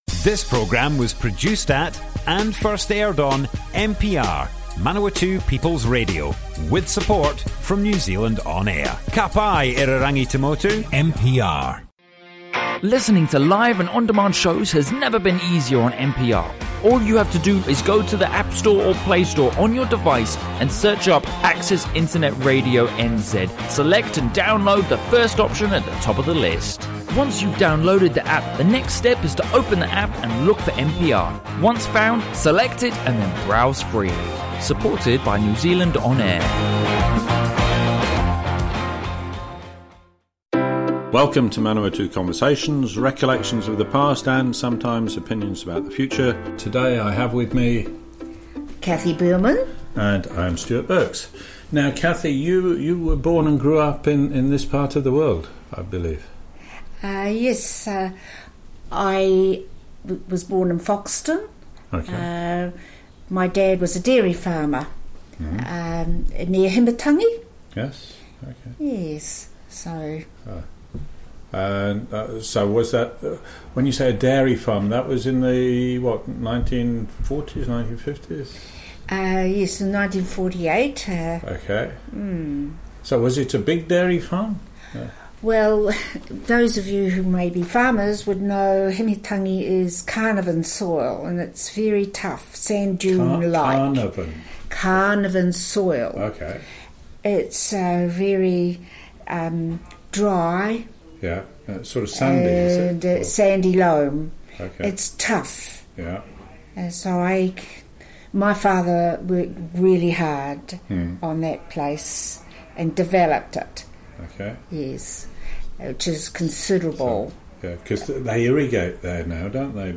Broadcast on Manawatū People's Radio on 15th October 2019. Born in Foxton, on dairy farm, 1950s, 212 cows.